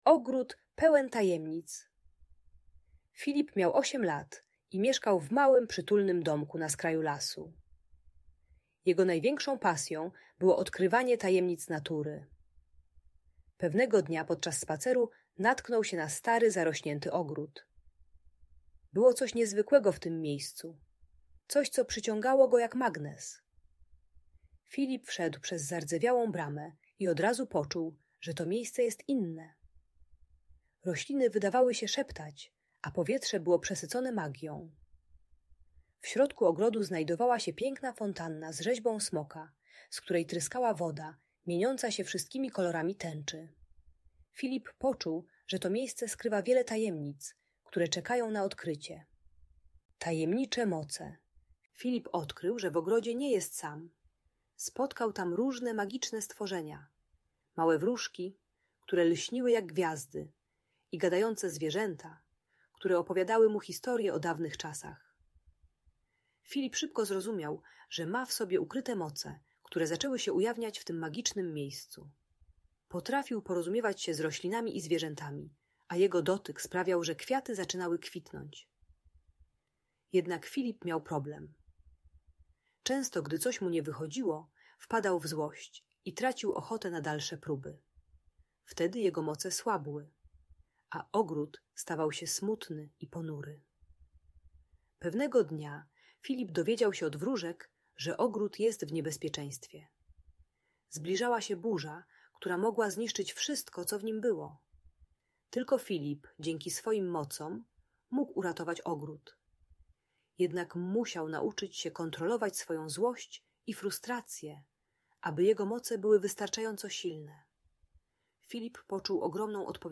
Magiczna historia o ogrodzie pełnym tajemnic - Bunt i wybuchy złości | Audiobajka